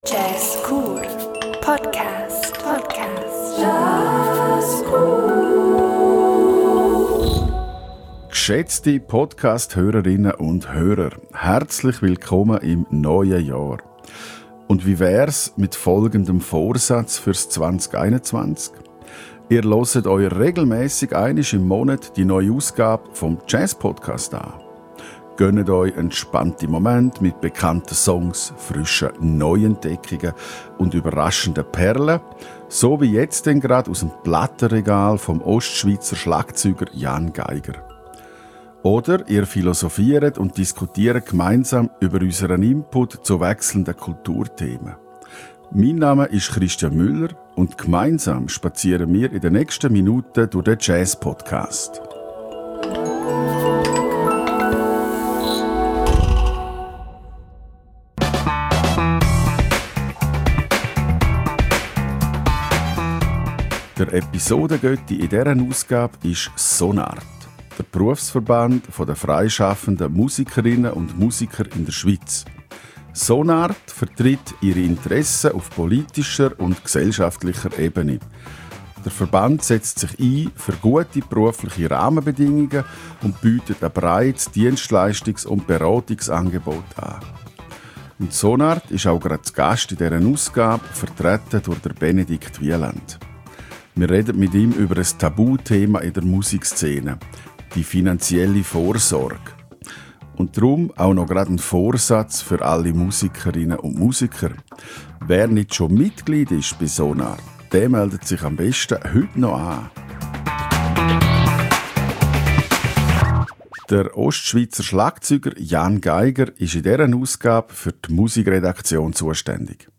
Musikalische Umrahmung